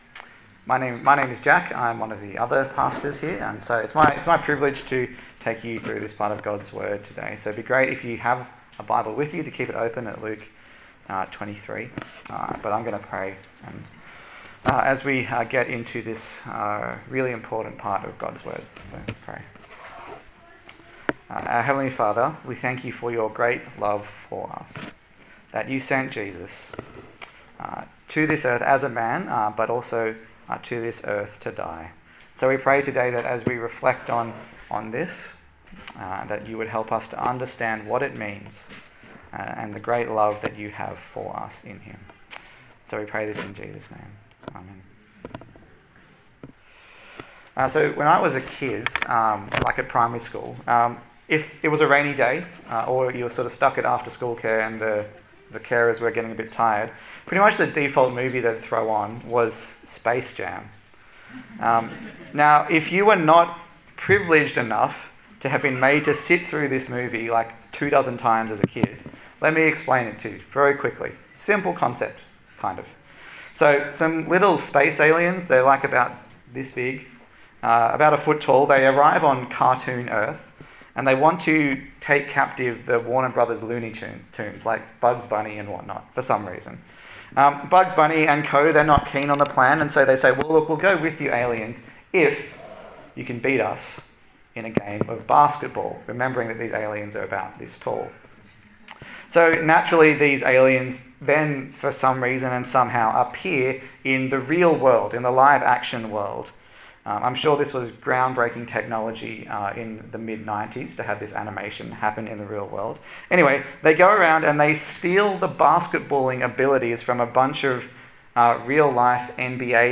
Luke Passage: Luke 23:26-56 Service Type: Good Friday